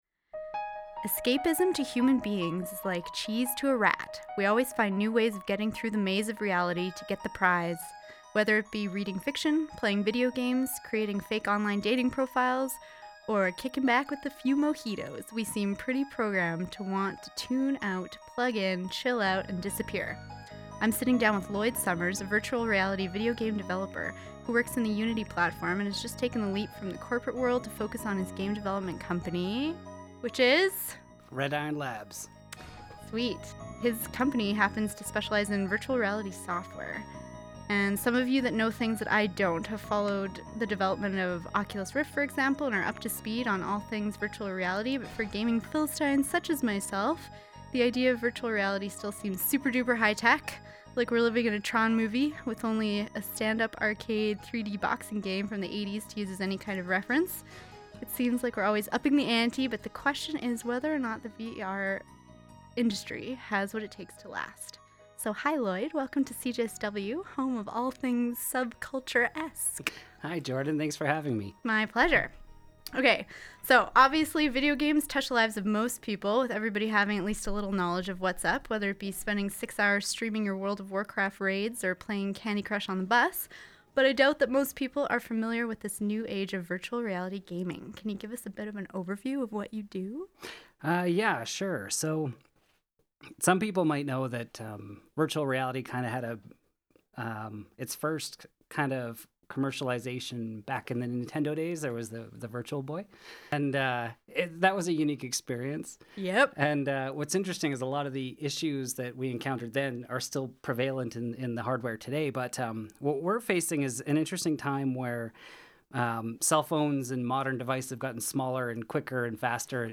CJSW Radio Interview Logos Need to put our logo in something?